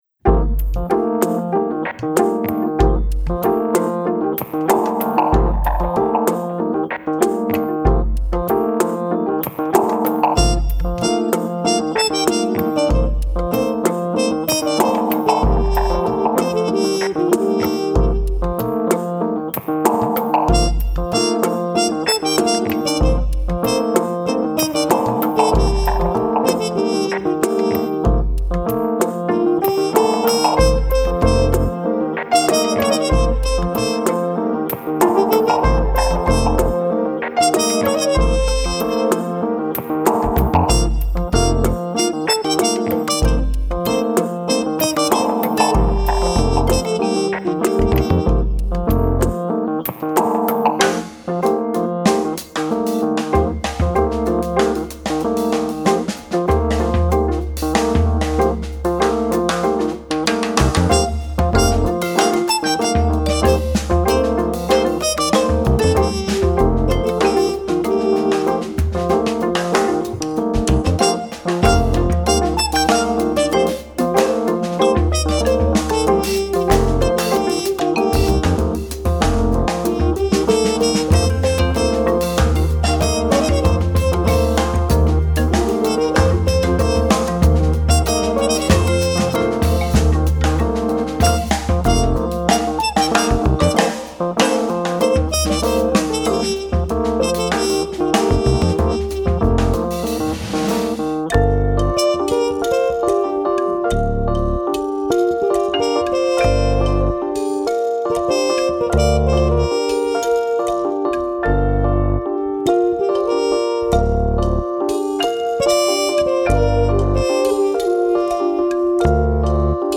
フィンランド発、スタイリッシュなクラブジャズサウンドが展開
フュージョン、クラブジャズ系ファンへオススメ。
keyboards, piano
trumpet, flugelhorn
double bass, bass guitar
drums
percussions